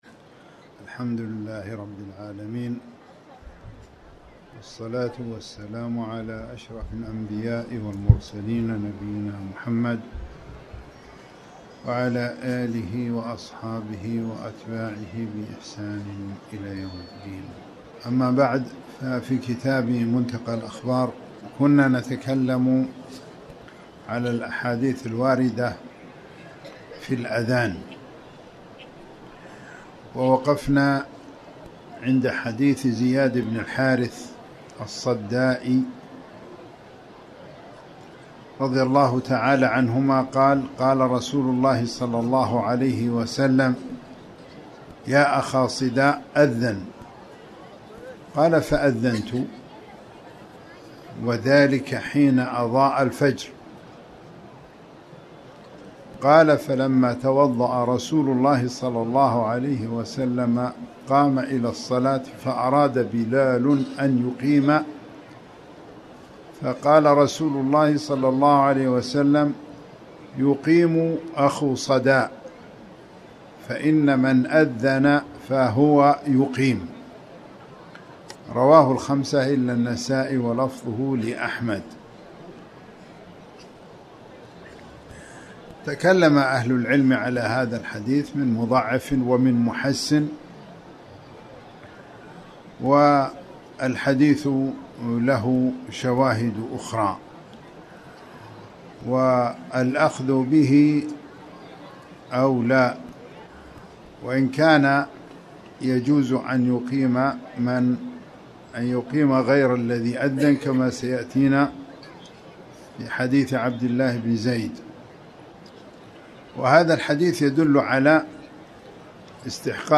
تاريخ النشر ٥ ربيع الأول ١٤٤٠ هـ المكان: المسجد الحرام الشيخ